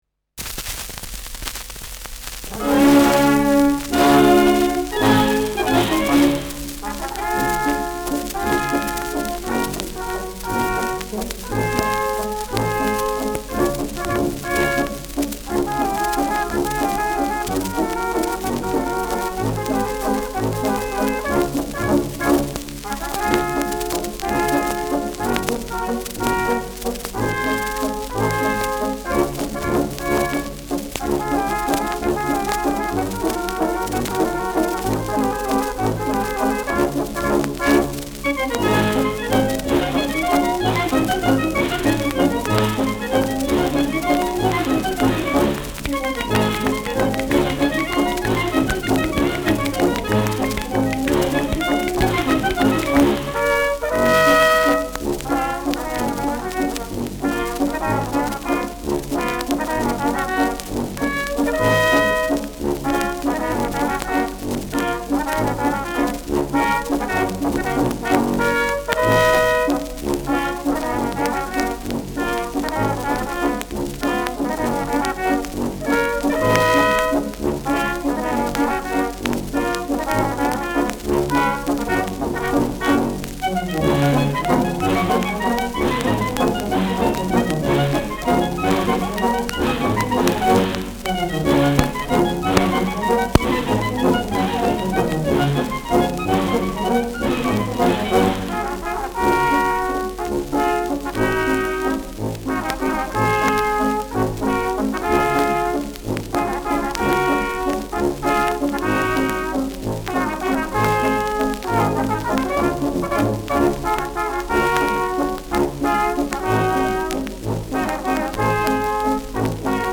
Schellackplatte
leichtes Rauschen : präsentes Knistern : präsentes Nadelgeräusch : gelegentliches Knacken
Kapelle Moar (Interpretation)
Mit Ausruf am Ende.
[Berlin] (Aufnahmeort)